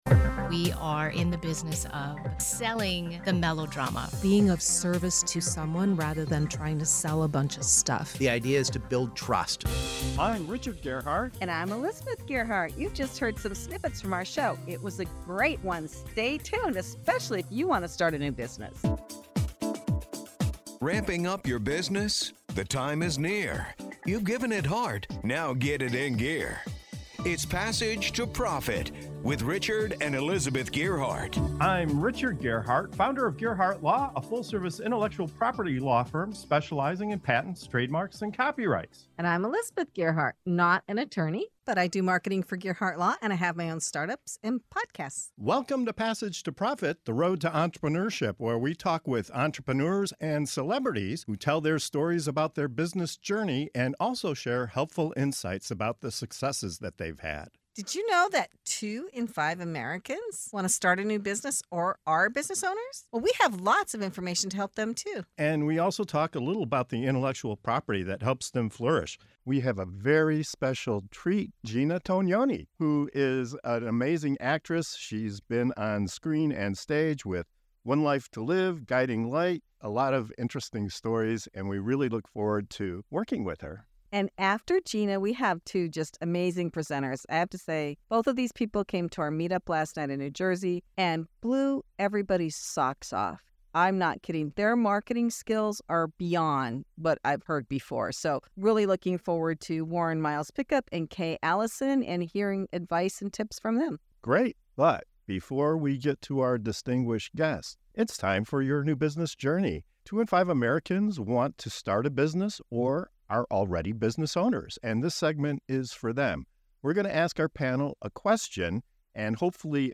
An Entrepreneur Reinvents: From Soap Star to Coach & Podcaster with Gina Tognoni + Others (Full Episode)